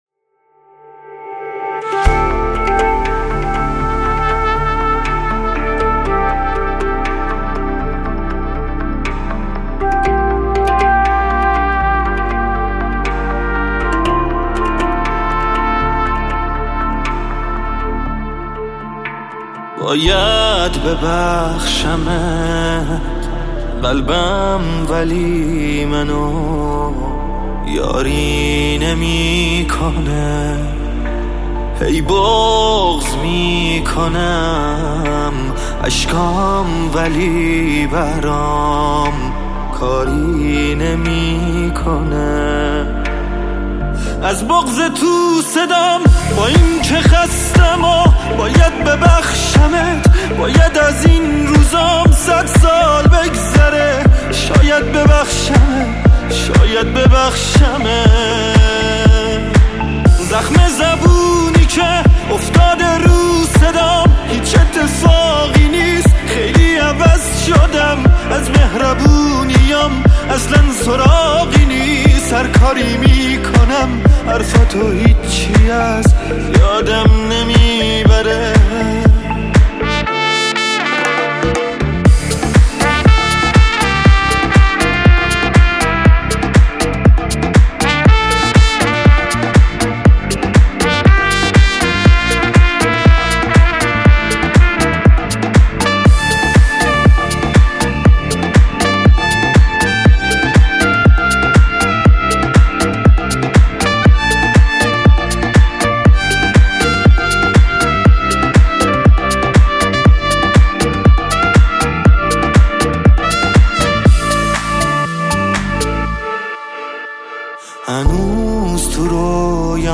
ممنون چه آهنگ سوز ناکی